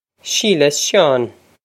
Pronunciation for how to say
Sheela ss Shawn
This is an approximate phonetic pronunciation of the phrase.